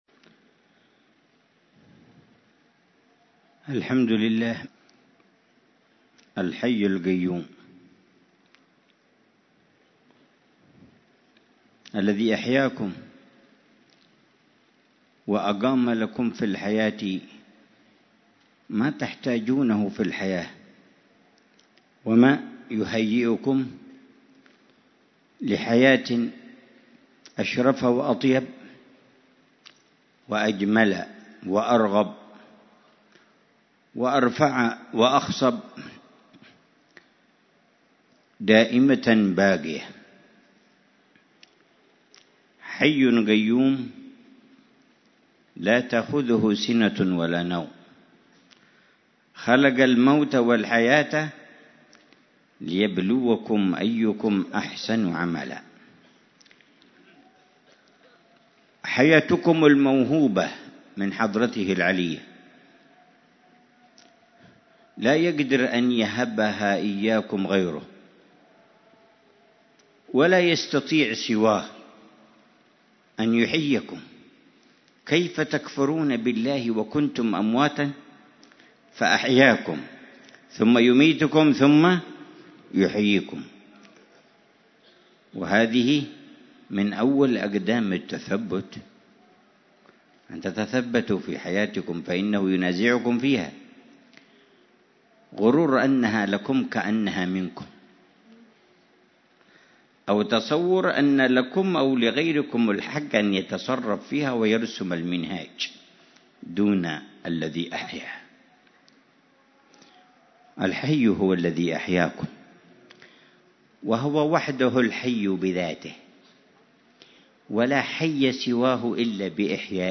محاضرة العلامة الحبيب عمر بن محمد بن حفيظ في مجلس الدعوة والتذكير في مسجد باعباد، ليلة الأحد 9 شعبان 1440هـ بعنوان: معاني التثبت وآثارها في الصلة بالحي القيوم والدعوة إليه.